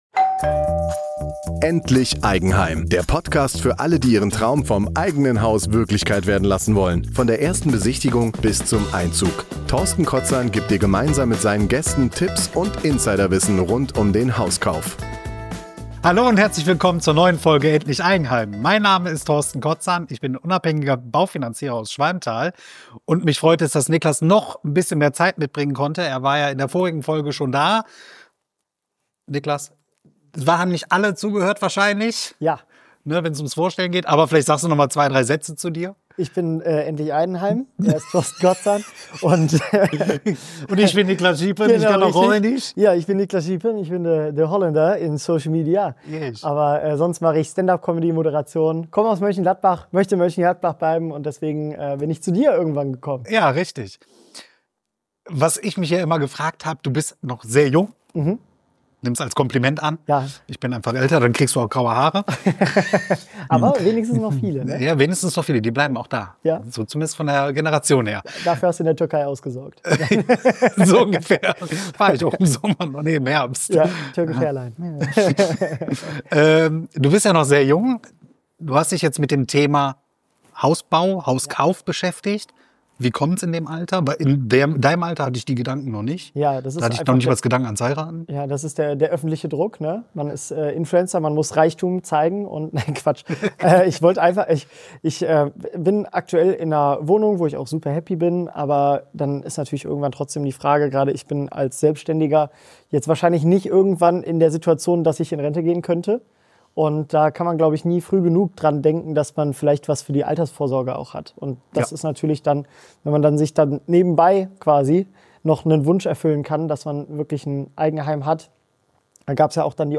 Im Gespräch erzählt er offen, warum er sich früh mit dem Thema Eigentum beschäftigt, welche Ängste und Zweifel ihn als Selbstständigen begleitet haben und warum für ihn das Eigenheim mehr ist als nur ein Zuhause – nämlich ein wichtiger Baustein der Altersvorsorge. Gemeinsam sprechen sie über frühe Entscheidungen rund um Immobilien, Hausbau vs. Hauskauf und die Bedeutung von Lage und Lebensplanung.